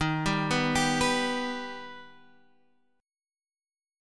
D#+ chord